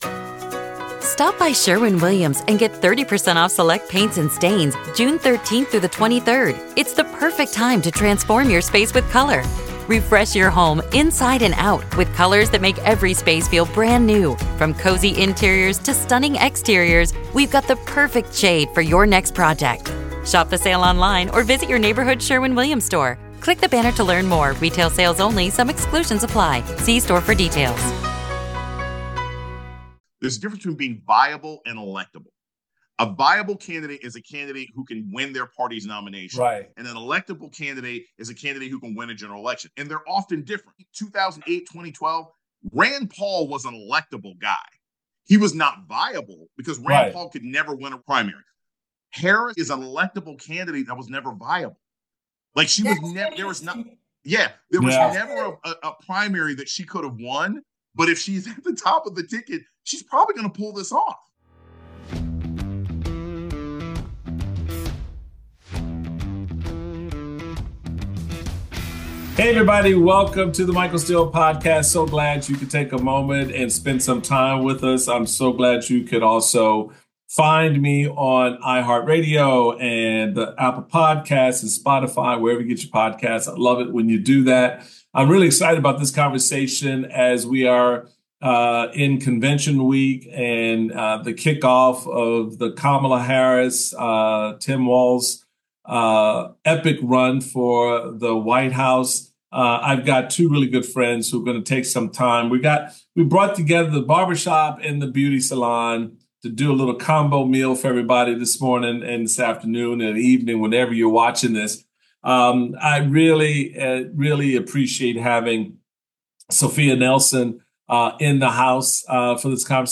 For this DNC-week barbershop edition of the podcast, Michael Steele speaks with political analysts Sophia Nelson and Dr. Jason Johnson.